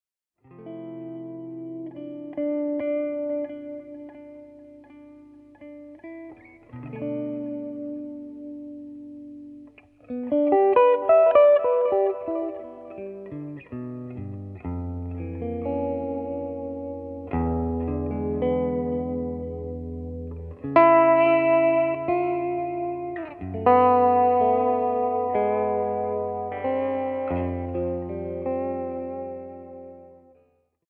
vocals
guitar
piano/Fender Rhodes
bass
drums